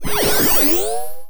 sci-fi_power_up_07.wav